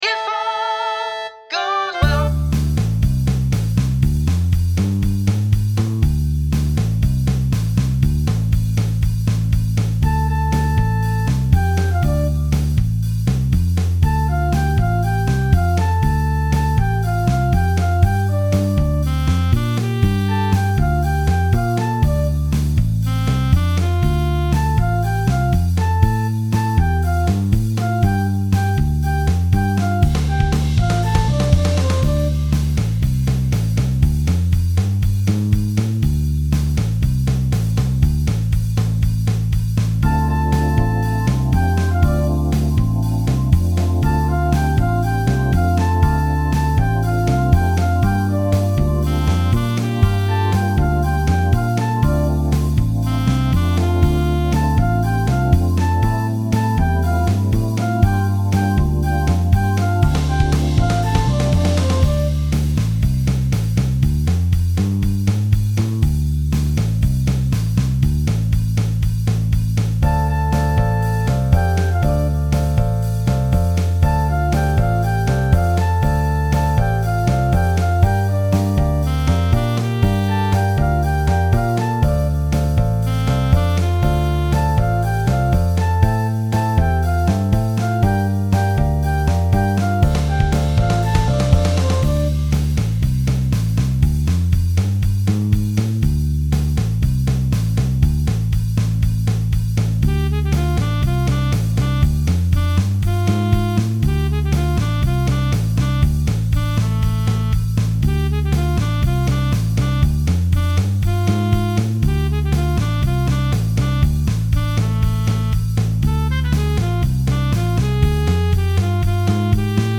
BluesRock
The vocal part here is a single-note organ.